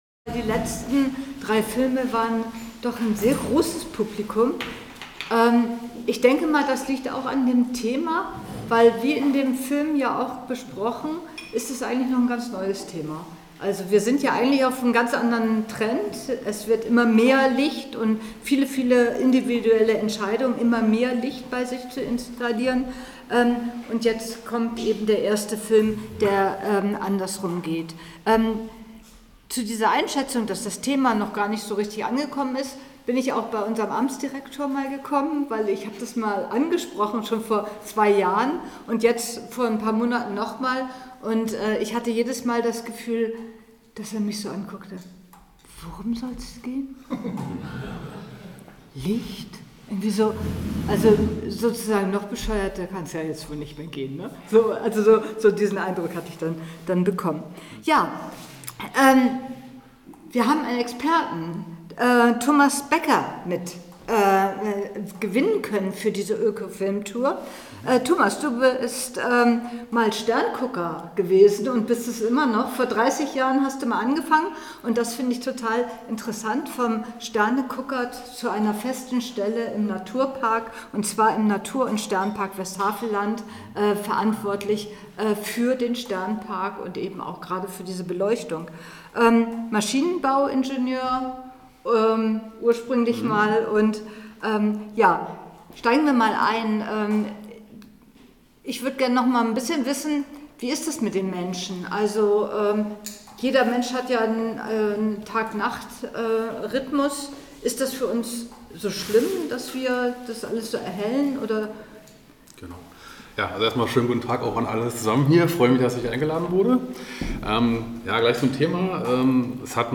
Publikumsgespräch